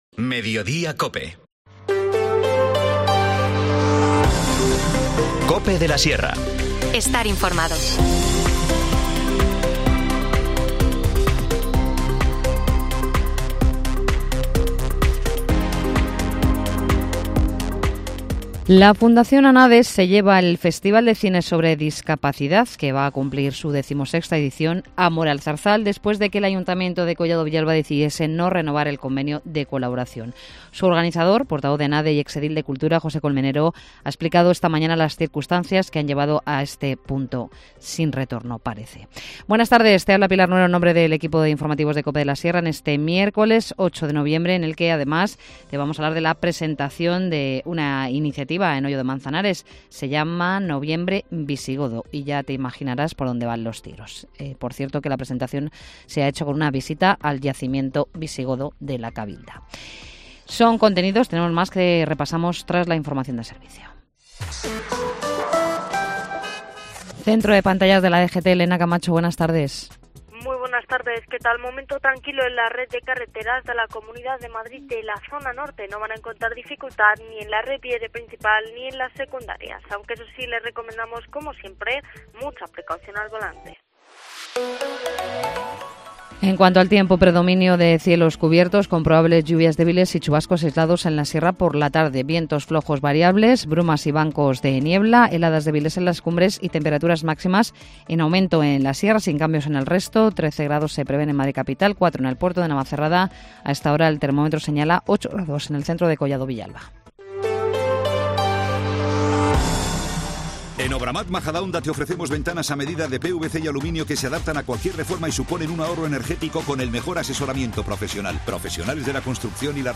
Informativo | Mediodía en Cope de la Sierra, 8 de noviembre de 2023